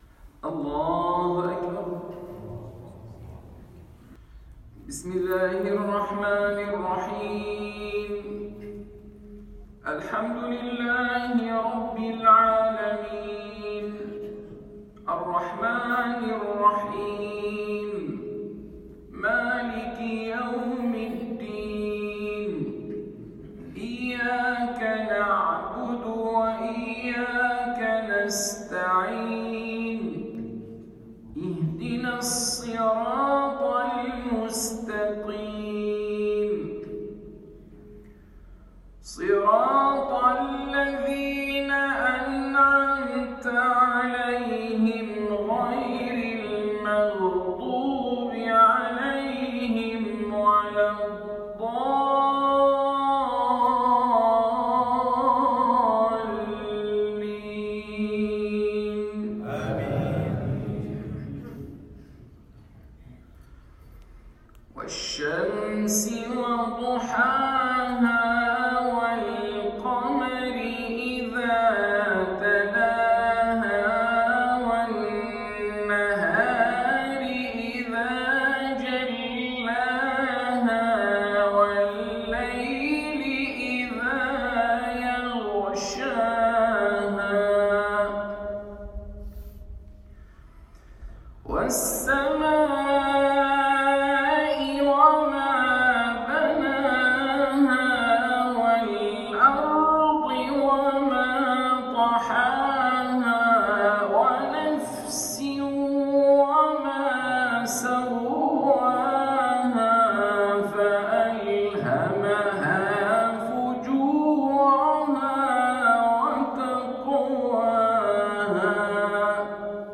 من صلاة المغرب في المسجد البحري